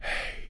描述：从纵横填字游戏的声音字节 字母，单词，线索。 2014年4月/ 5月，在Syracuse大学的Logic中录制。
声道立体声